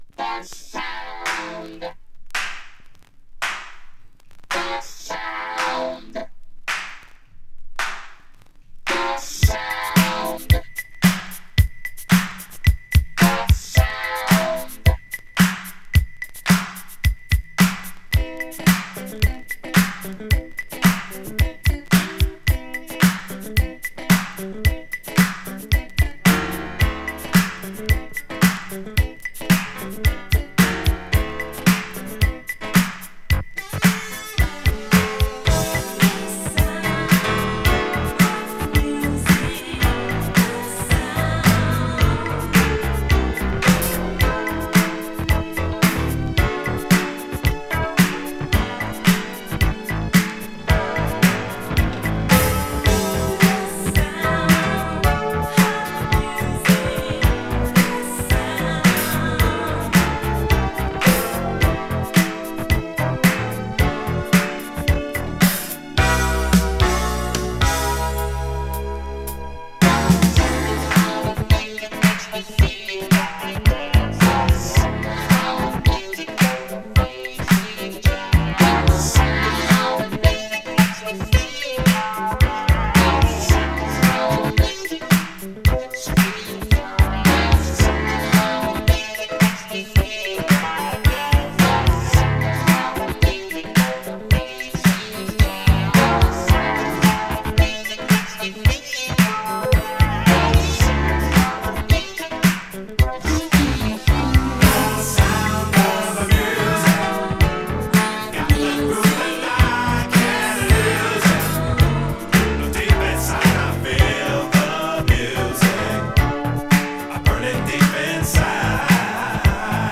音圧のある45RPM12inch!!